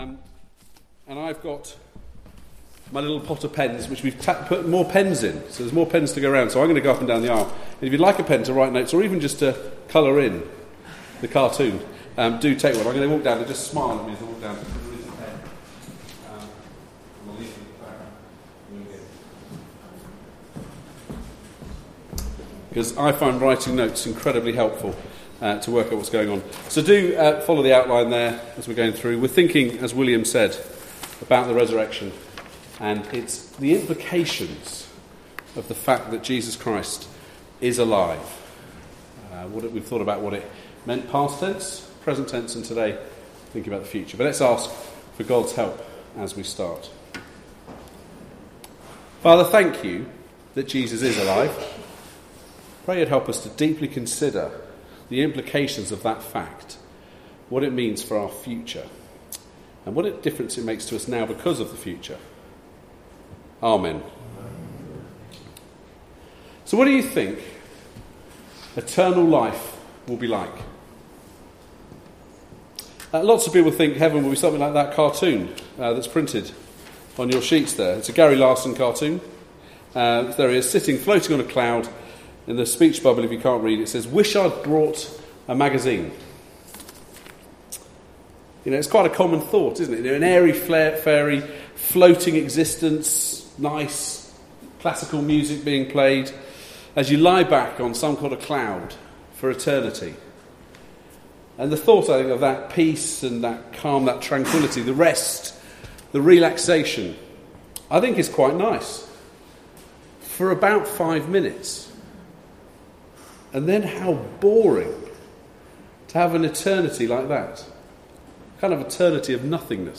Passage: Revelation 21:1-8 & 1 Peter 1:3-9 Service Type: Weekly Service at 4pm